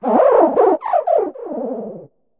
Cri de Pohmarmotte dans Pokémon HOME.